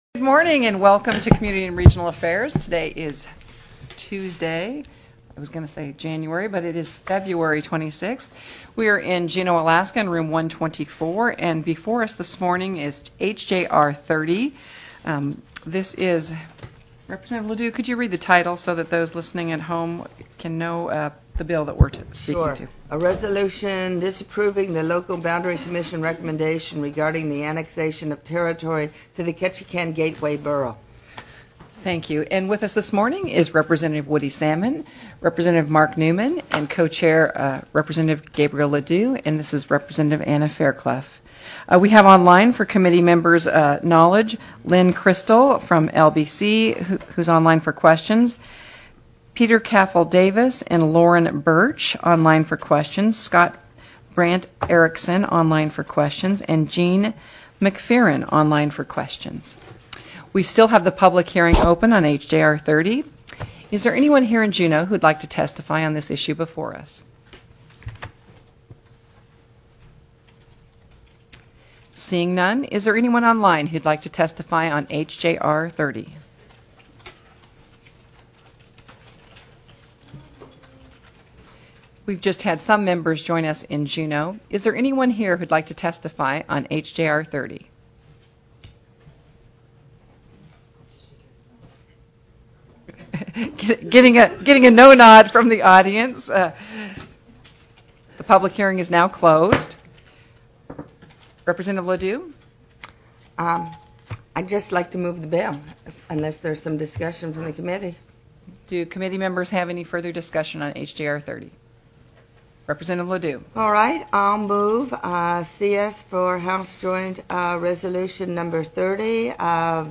+= HJR 30 DISAPPROVING KETCHIKAN ANNEXATION TELECONFERENCED
CO-CHAIR  FAIRCLOUGH,  upon determining  no  one  else wished  to